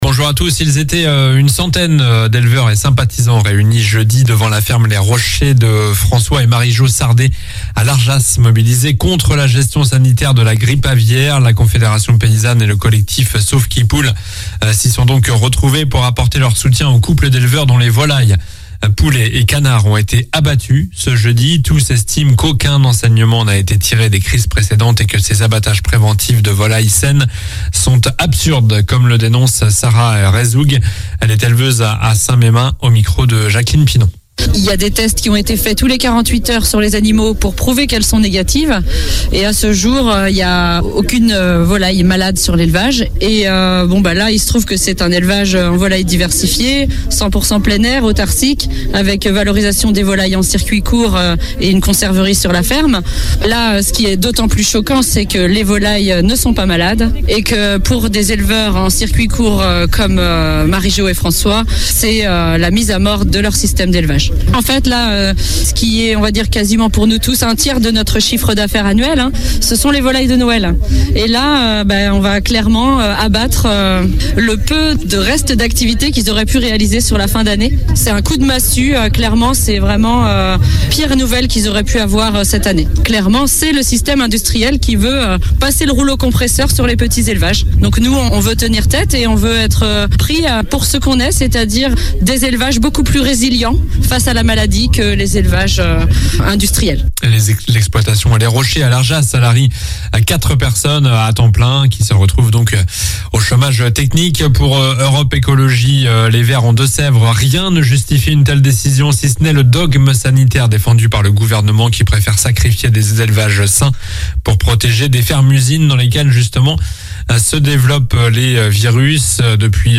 Journal du samedi 12 novembre